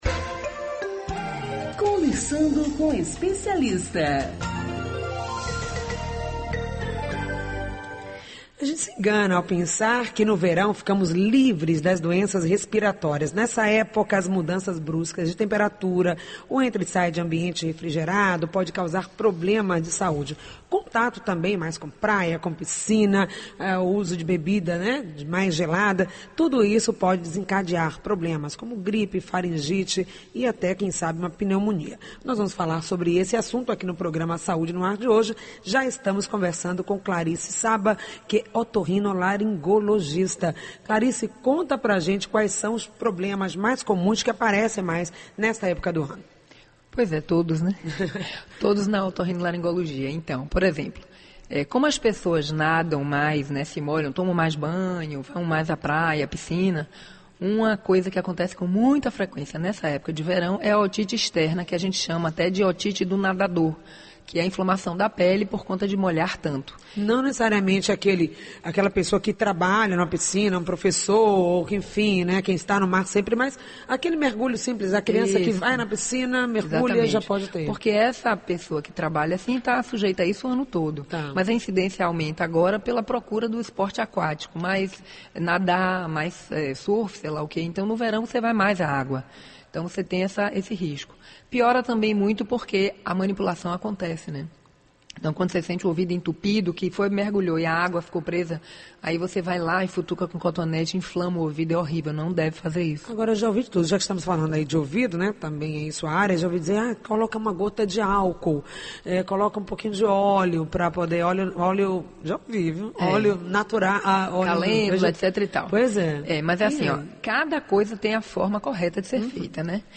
O programa Saúde No Ar vai ao ar de segunda à sexta-feira, das 10 às 11h, pela Rede Excelsior de comunicação: AM840, AM Recôncavo 1.460 e FM 106.1 e também pela Rádio Saúde no ar, (aplicativo gratuito).